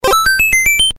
Tesla Lock Sounds & Chimes Collection: Movies, Games & More - TeslaMagz